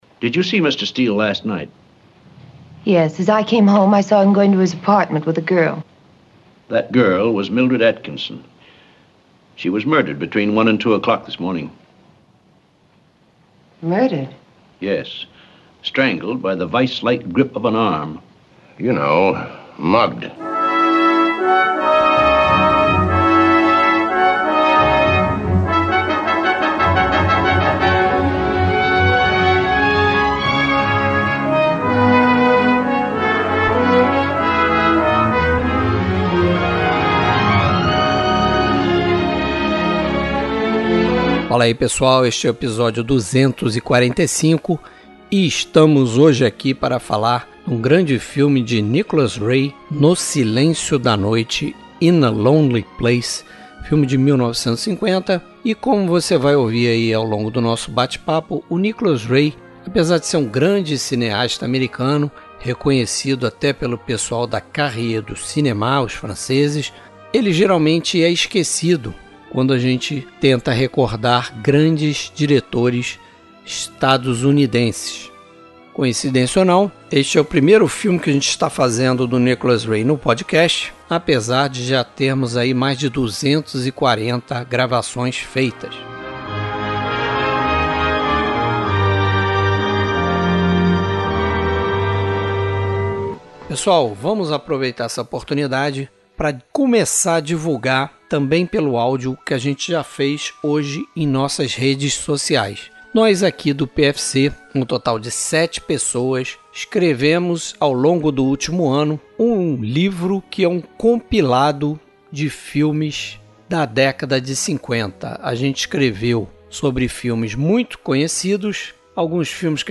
Crédito da Foto: Columbia Pictures Trilha Sonora: músicas compostas para este filme .